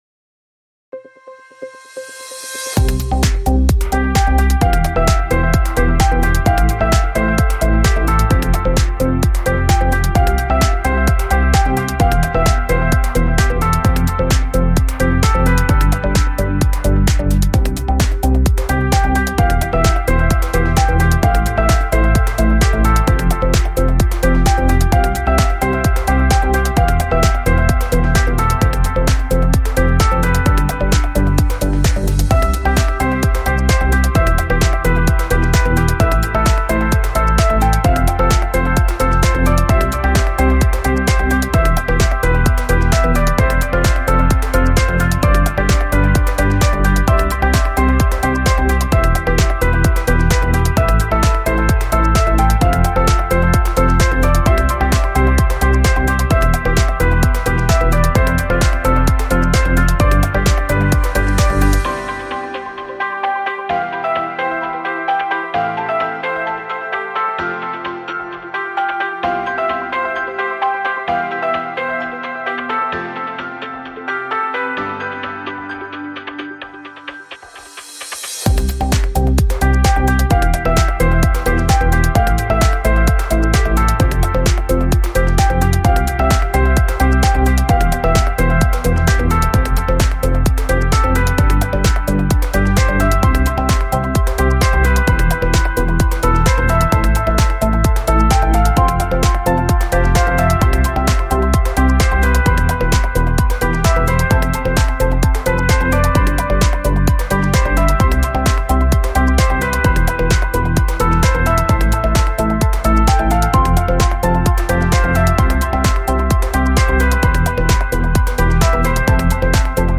Энергичная танцевальная музыка для видео на фон монтажа